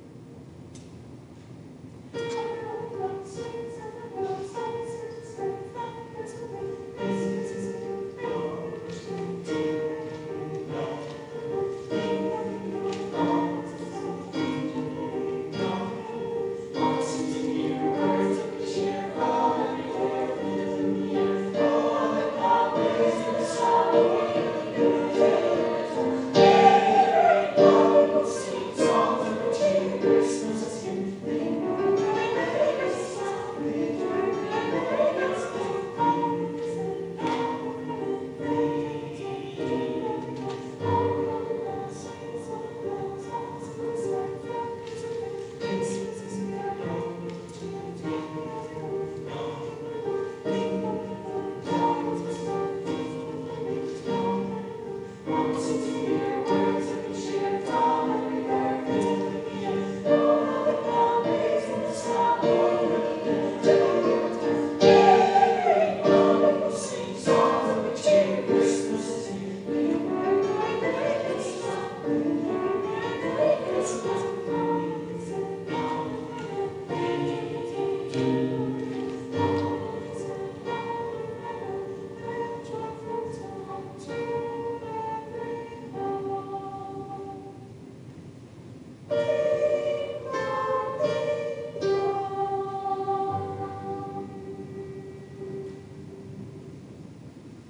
9-12 Grade Sr. High Choir -